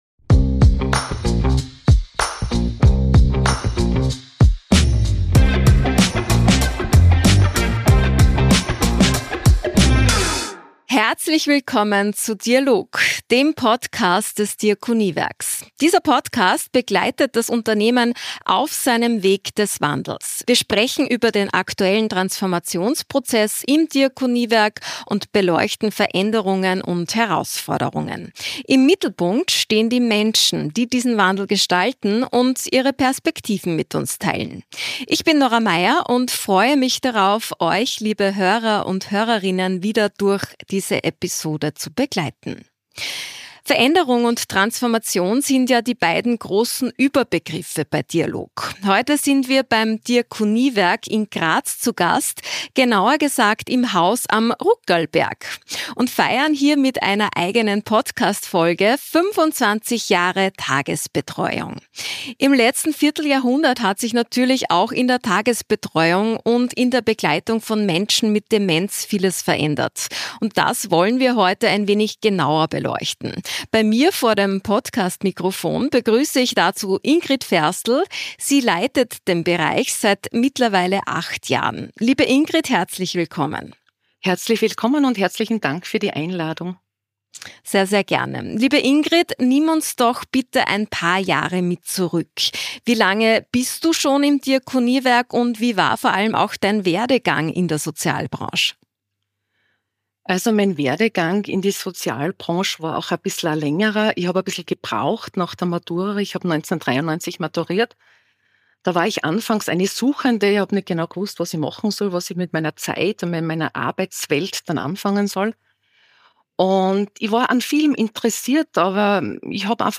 Ein Gespräch über gelebte Menschlichkeit, neue Ansätze in der Begleitung und große Wünsche für die Zukunft.